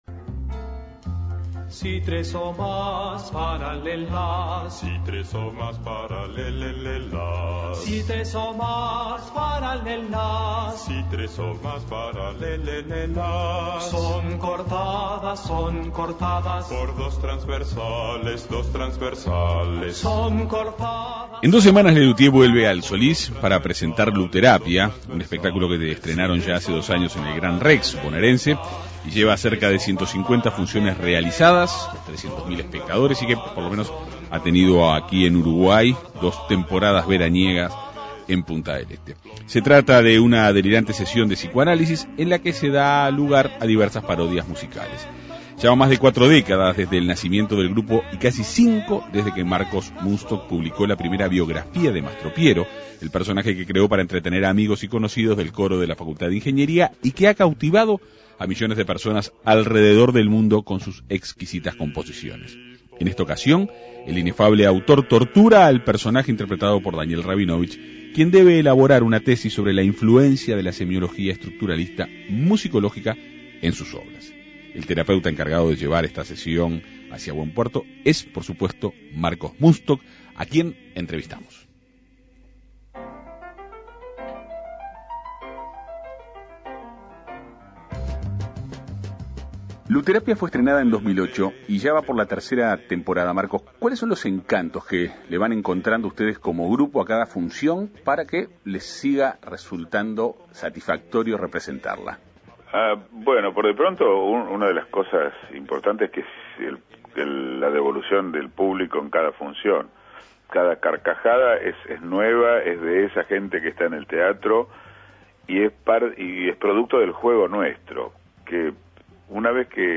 El terapeuta encargado de llevar la sesión hacia buen puerto es Marcos Mundstock, quien fue entrevistado en la Segunda Mañana de En Perspectiva.